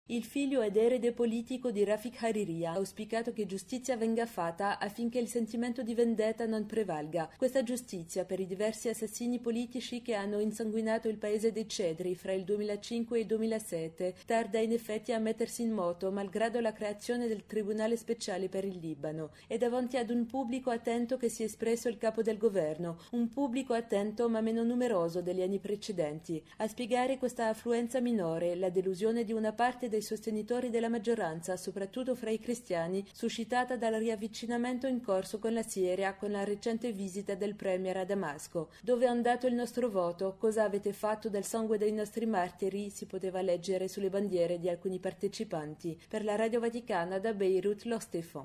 Da Beirut